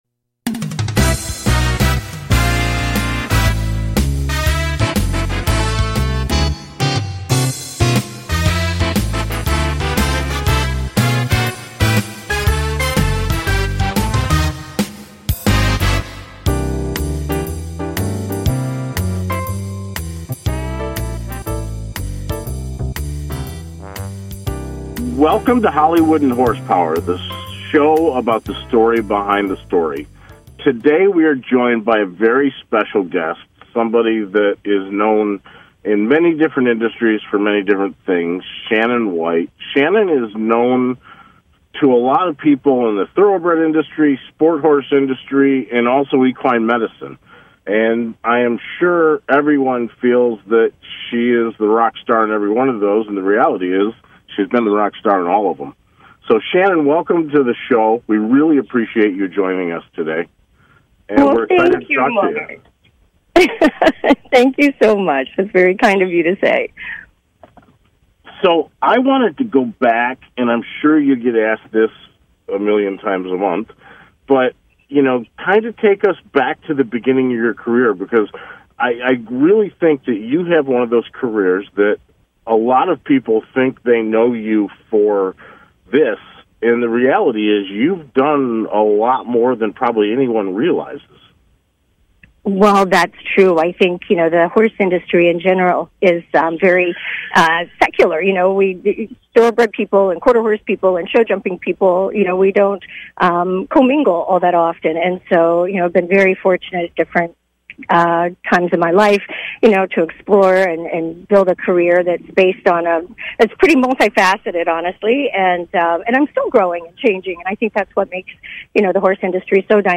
Talk Show
It is where SNL meets The Tonight Show; a perfect mix of talk and comedy.